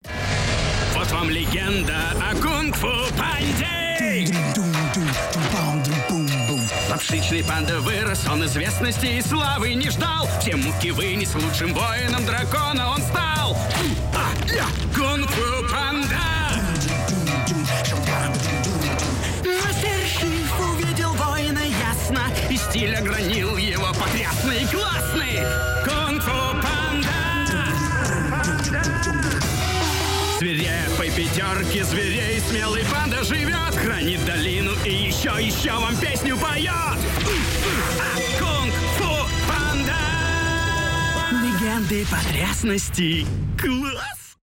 ost